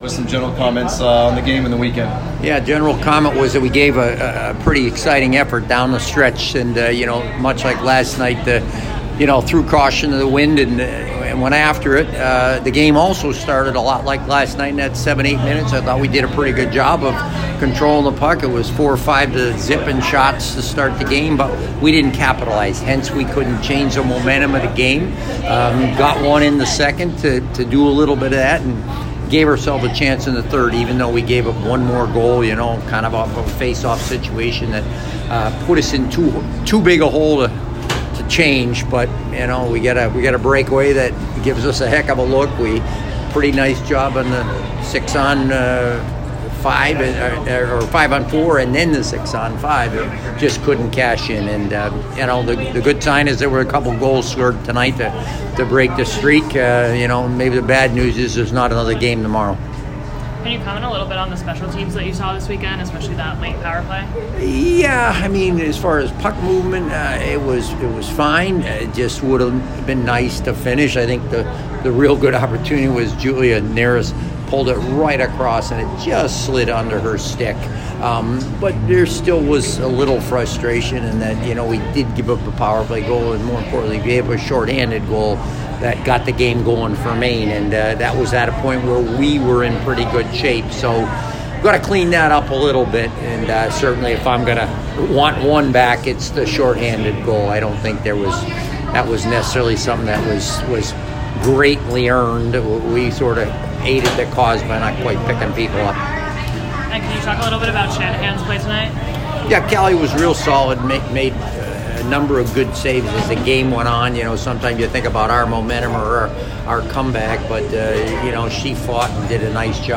Women’s Ice Hockey / Maine Postgame Interview